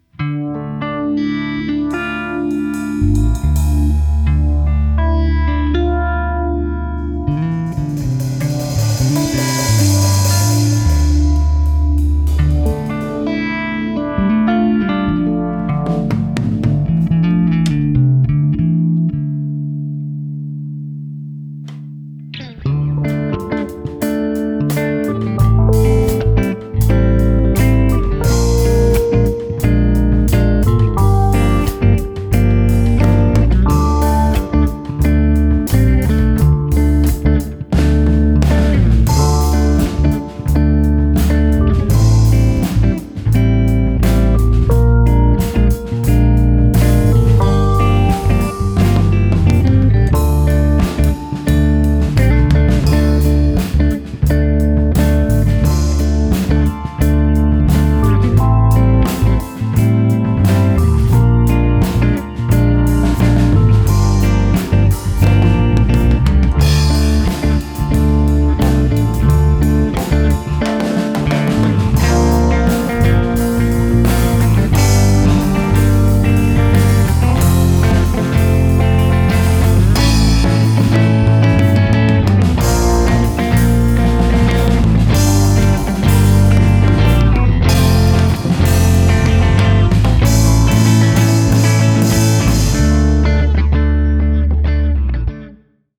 Studio Jam.wav